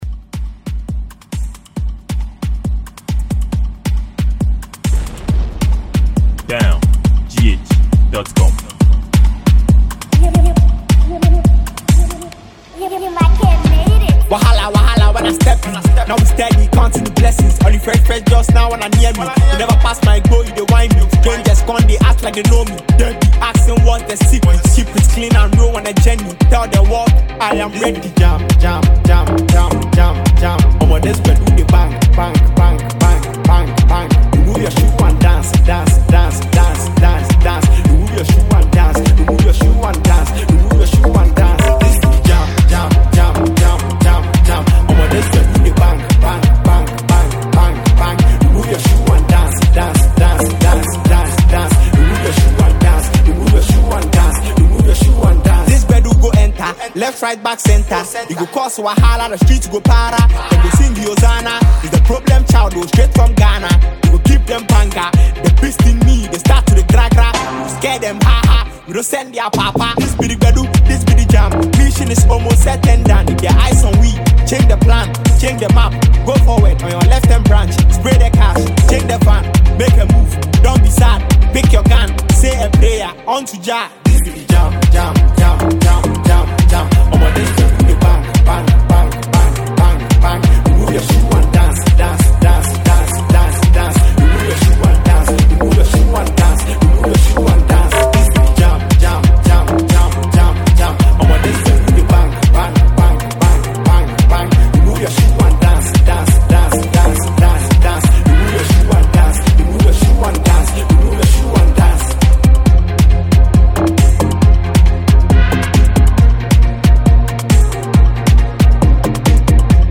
Ghanaian fast-rising afrobeat musician